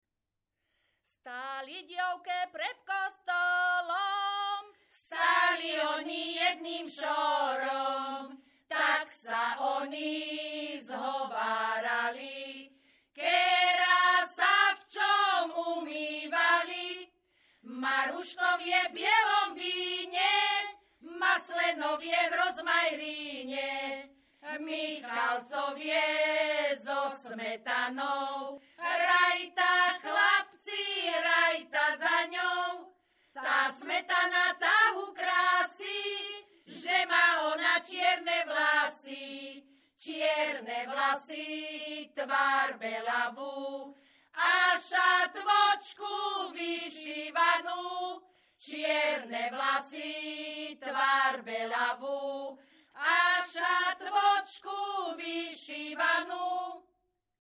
Descripton ženský skupinový spev bez hudobného sprievodu
Place of capture Brehy
Key words ľudová pieseň